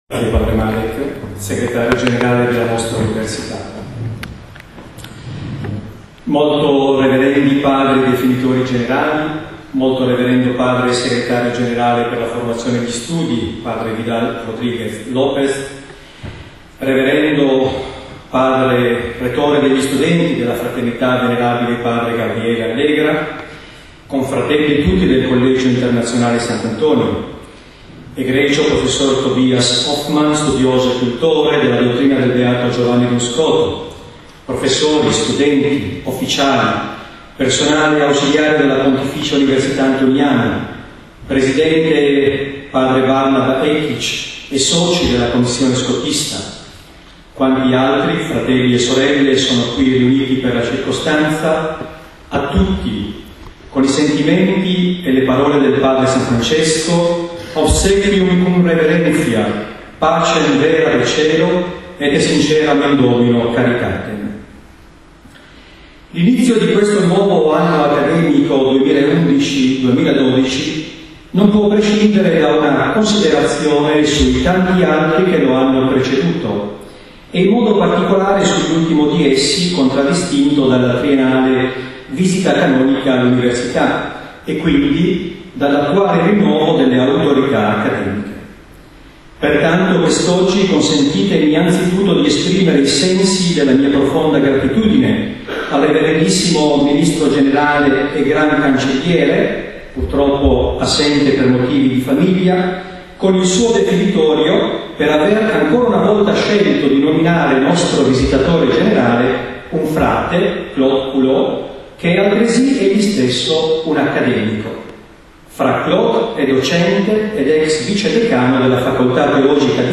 In Italian: AUDIO: Rettore Magnifico, Discorso e relazione sull'anno accademico 2010-2011